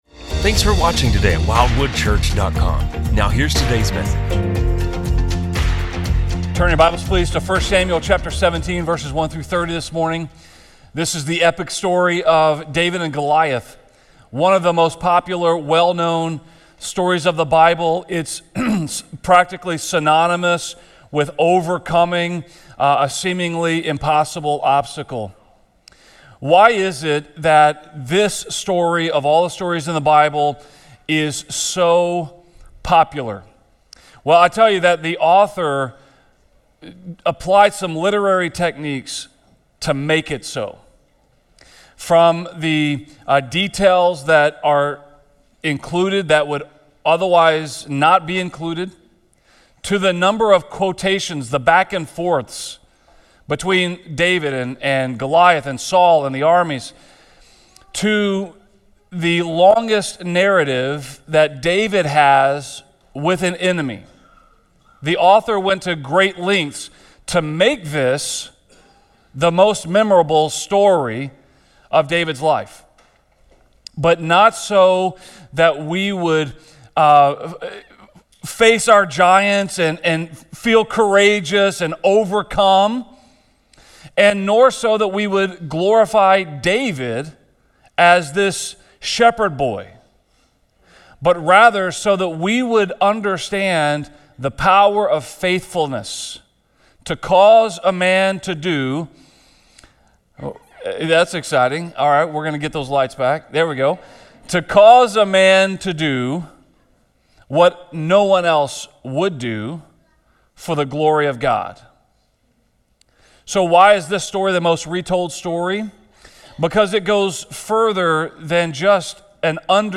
A message from the series "The Life of David." When faced with the opportunity to get even, what you choose to do often reveals what you believe about God - about His care for you, His justice, and His mercy.